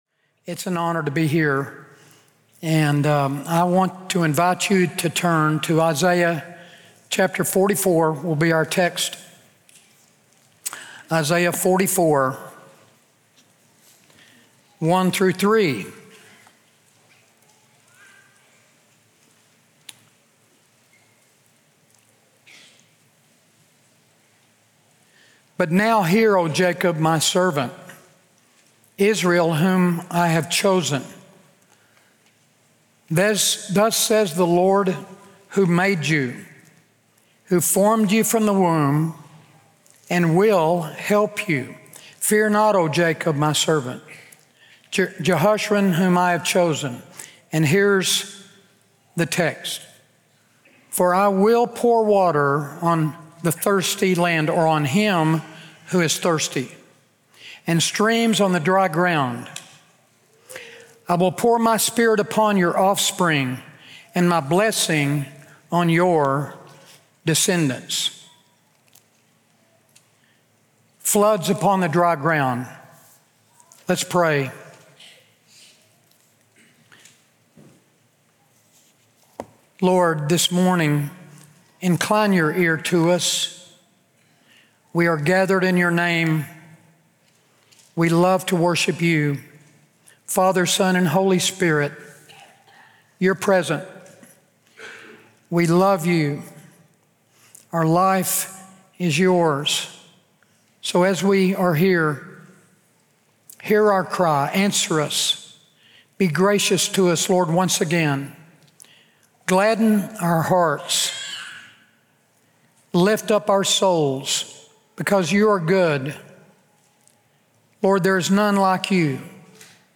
Sermons | Immanuel Baptist Church
Guest Speaker